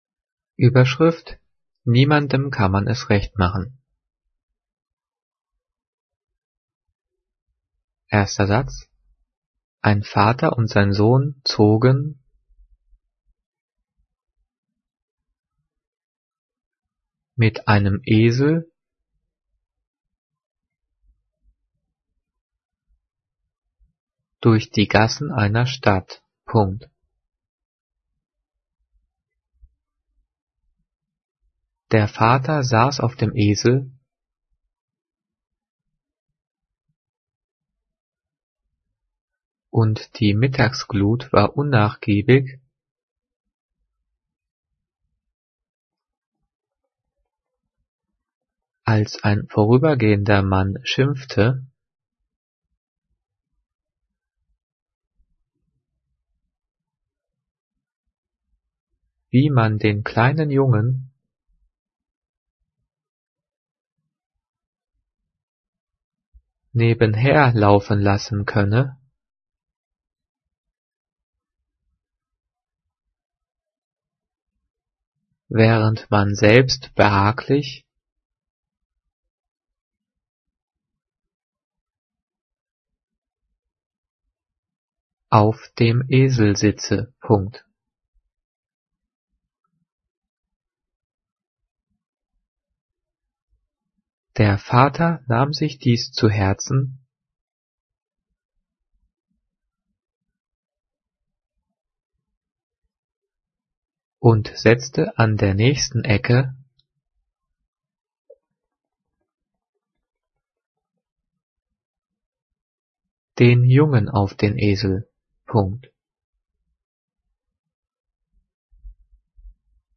Diktiert: